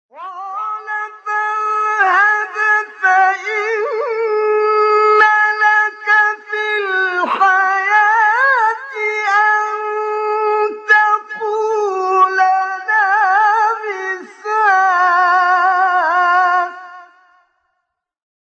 گروه شبکه اجتماعی: فرازهای صوتی اجرا شده در مقام حجاز با صوت کامل یوسف البهتیمی ارائه می‌شود.
برچسب ها: خبرگزاری قرآن ، ایکنا ، شبکه اجتماعی ، مقاطع صوتی ، مقام حجاز ، کامل یوسف البهتیمی ، قاری مصری ، تلاوت قرآن ، قرآن ، iqna